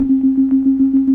Index of /90_sSampleCDs/Keyboards of The 60's and 70's - CD1/KEY_Optigan/KEY_Optigan Keys